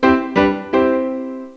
zep_piano.wav